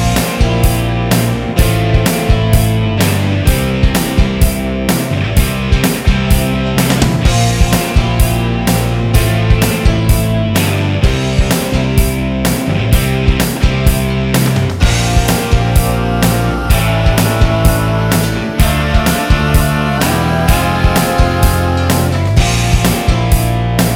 no Piano Pop (1980s) 4:27 Buy £1.50